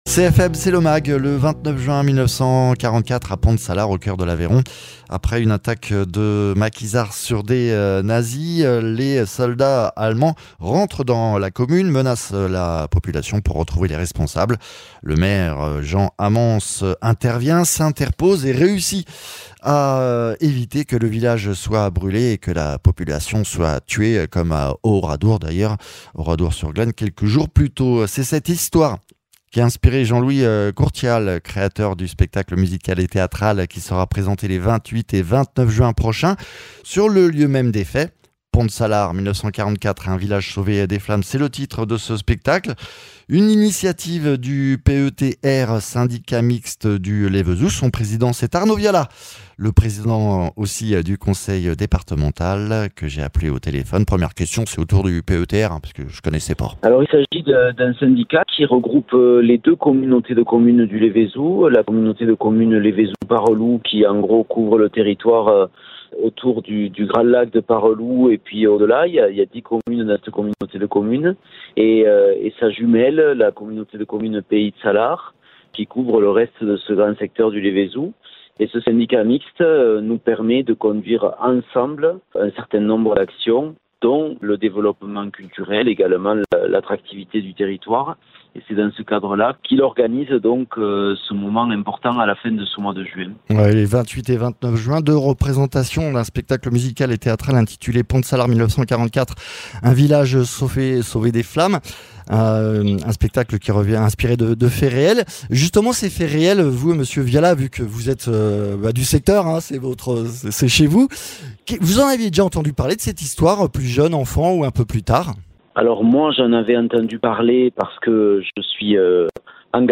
Interviews
Invité(s) : Arnaud Viala, Président du conseil départemental de l’Aveyron et Président du PETR Syndicat mixte du Lévézou qui organise l’événement